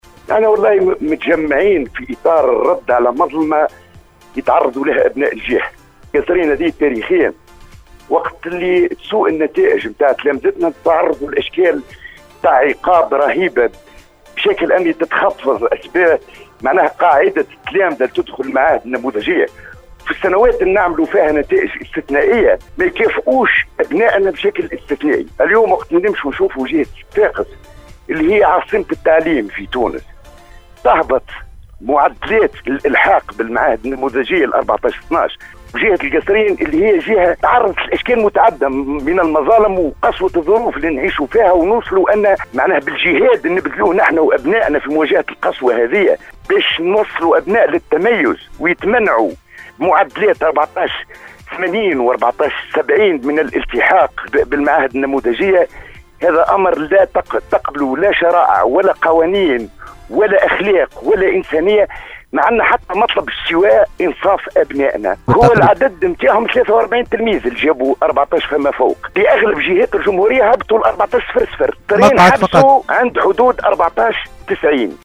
وقفة-احتجاجيّة-امام-المندوبية-.mp3